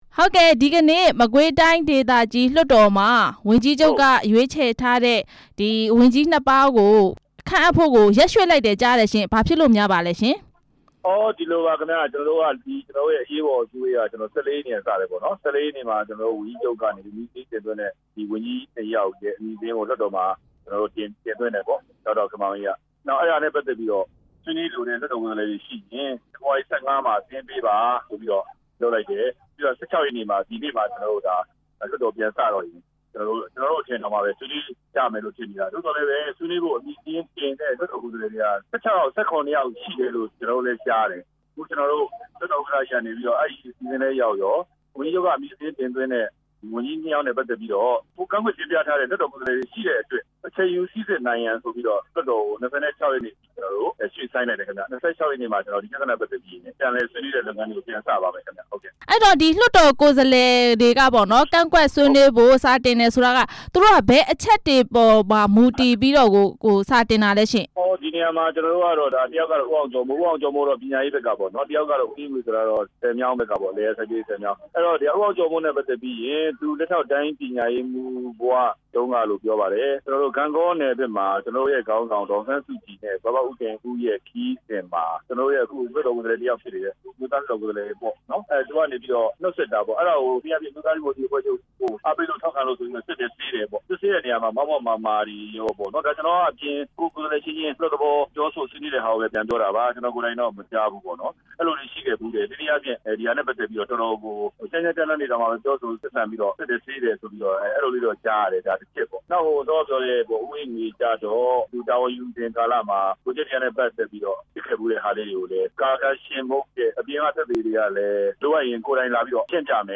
မင်းတုန်းမြို့နယ် တိုင်းဒေသကြီး လွှတ်တော် ကိုယ်စားလှယ် ဦးအေးလှိုင်ဦး နဲ့ ဆက်သွယ်မေးမြန်းချက်